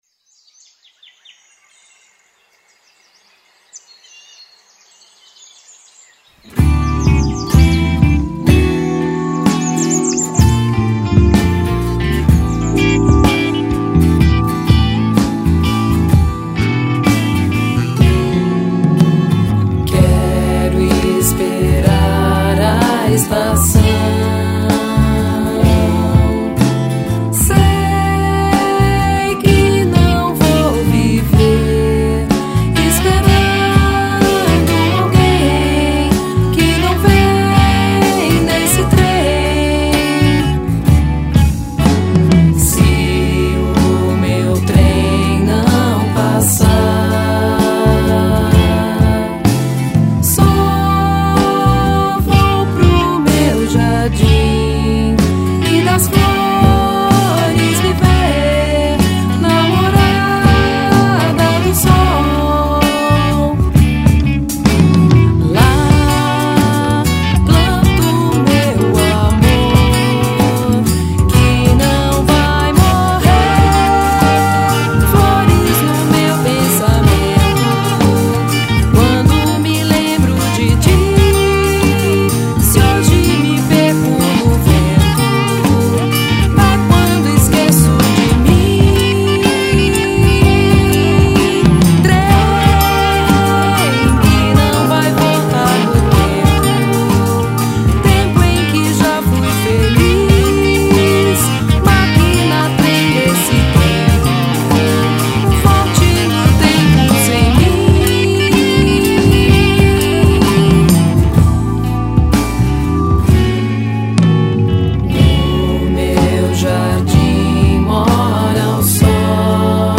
EstiloRock Progressivo